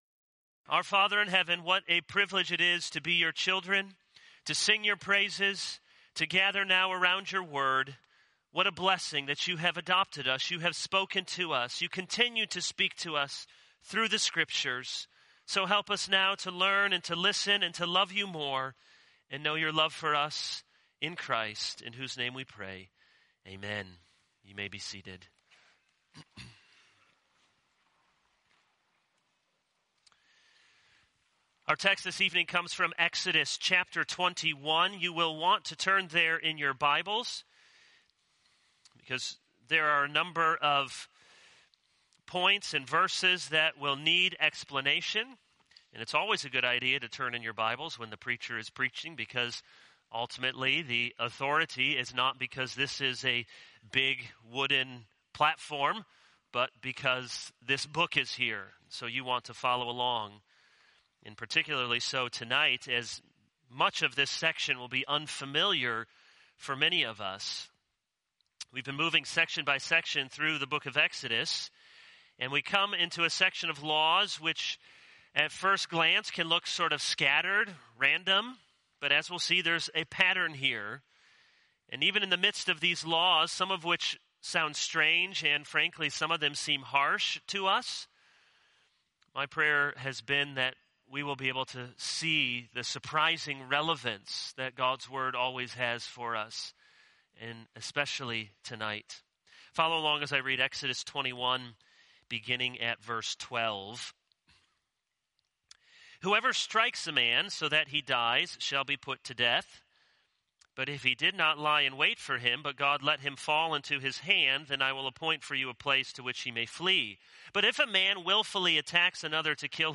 This is a sermon on Exodus 21:12-26.